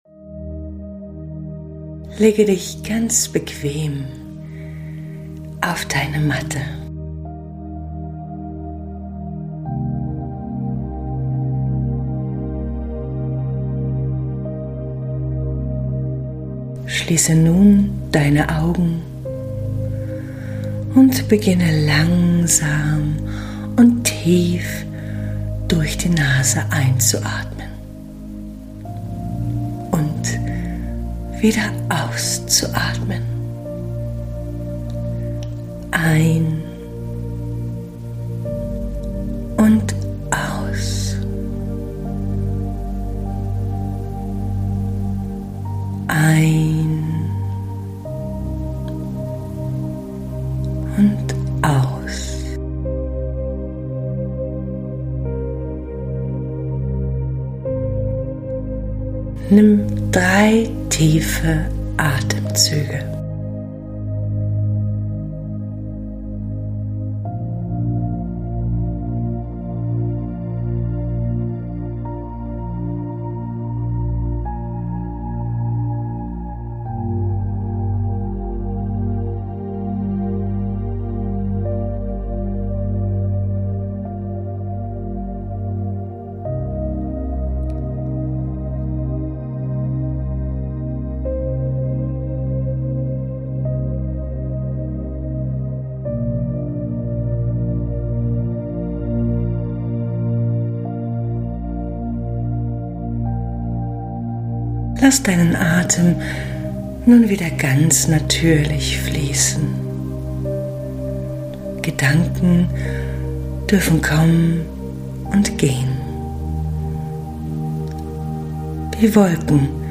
Beschreibung vor 10 Monaten Im Rahmen unseres Retreats am 17.05.2025 im schönen Roten Haus bei Moritzburg haben wir diese Fantasiereise in die Zukunft mit unseren Teilnehmerinnen genießen dürfen. Lege dich gern ganz gemütlich hin und unternimm eine Gedankenreise in deine wundervolle, positive Zukunft.